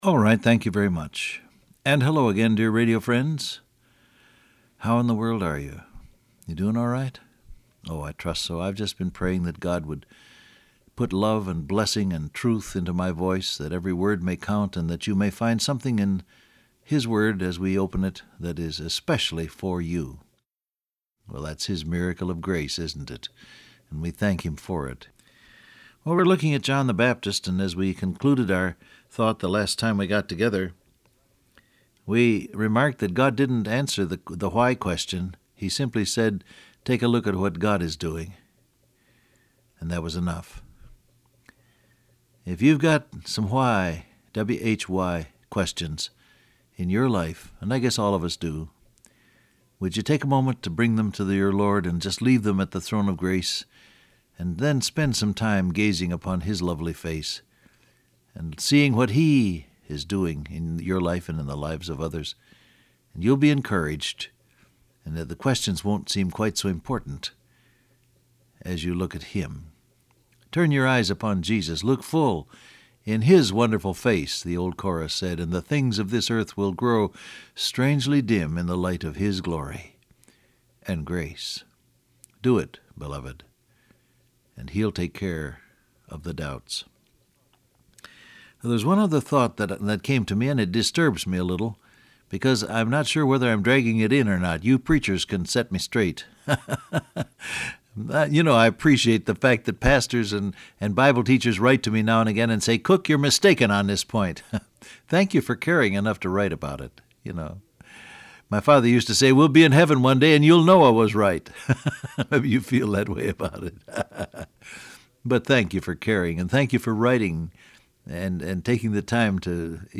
Download Audio Print Broadcast #6421 Scripture: Mark 6 , John 4 Topics: God's Will , Your Job , Time Transcript Facebook Twitter WhatsApp Alright thank you very much, and hello again dear radio friends.